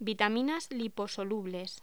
Locución: Vitaminas liposolubles
voz